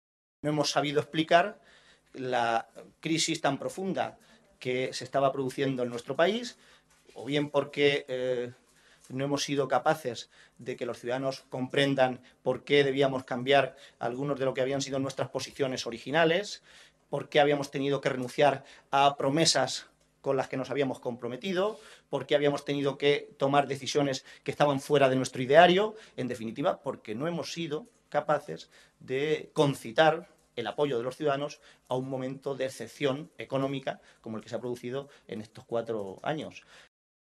Así se expresaba el secretario de Organización regional, José Manuel Caballero, esta tarde en rueda de prensa, tras la celebración de la Ejecutiva regional.